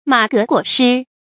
注音：ㄇㄚˇ ㄍㄜˊ ㄍㄨㄛˇ ㄕㄧ
馬革裹尸的讀法